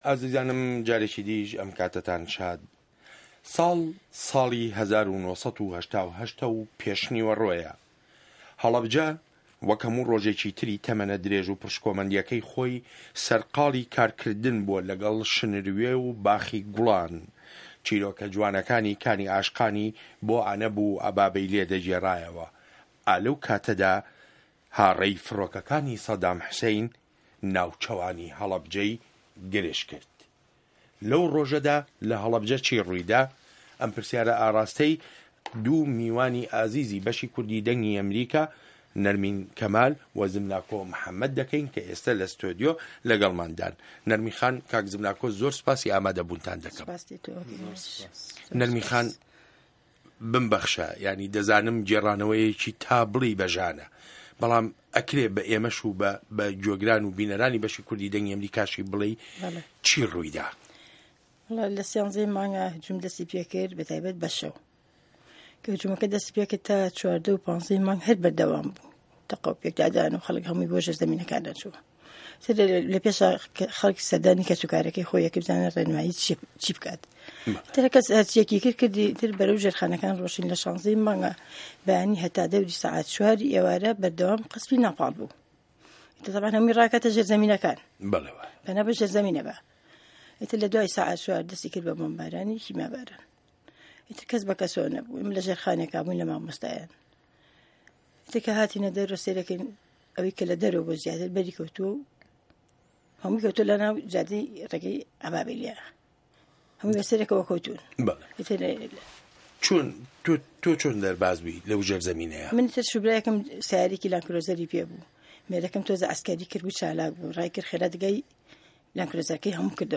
مێزگرد : هه‌ڵه‌بجه‌ له‌ زاری قوربانیه‌ ده‌ربازبووه‌کانه‌وه‌